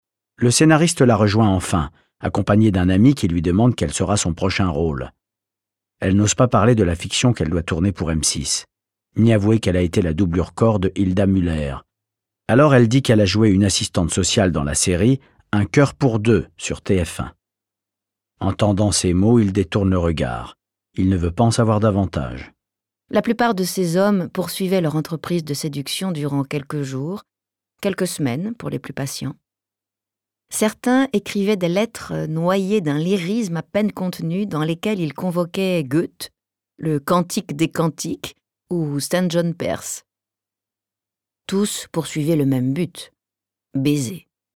Livres Audio